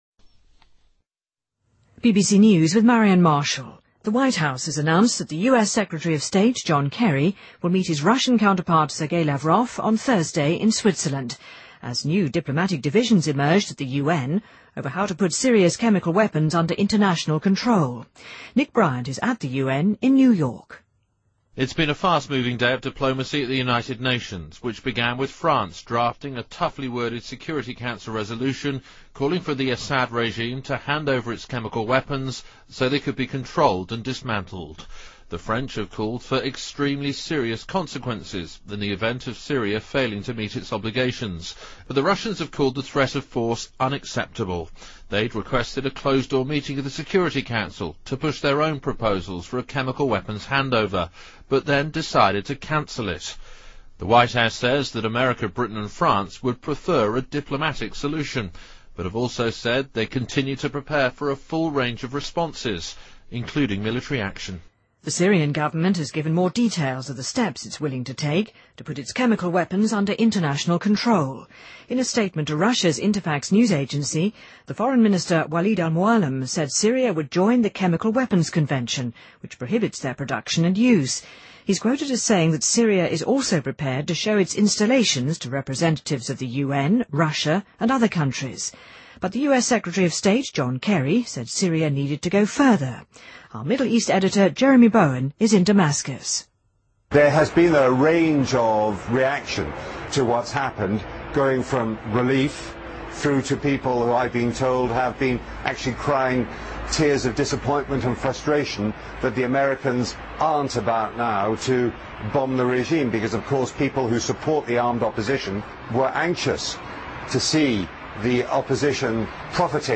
BBC news,国际奥委会选举德国律师兼IOC副主席托马斯·巴赫担任新主席